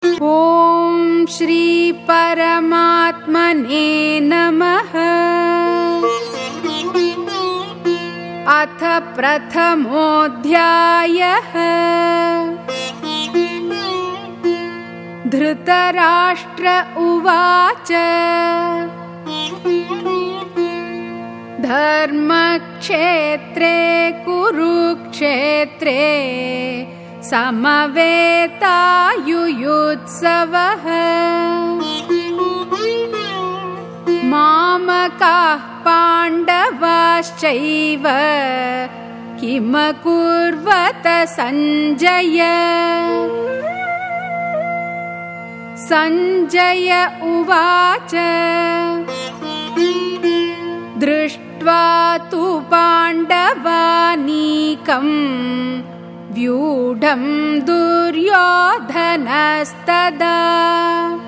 Geeta Chanting